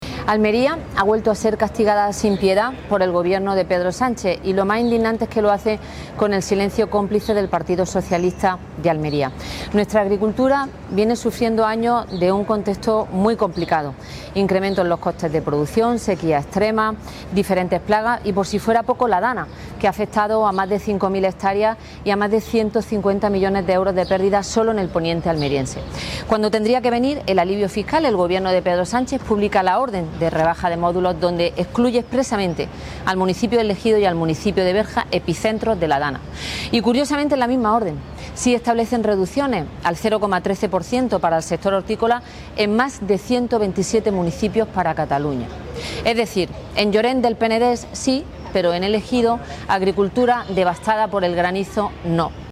La parlamentaria autonómica Julia Ibáñez ha comparecido en rueda de prensa para anunciar una iniciativa en el Parlamento de Andalucía exigiendo al Gobierno de España que rectifique de inmediato y extienda esta medida fiscal a los agricultores del Poniente almeriense y del conjunto del campo de la provincia.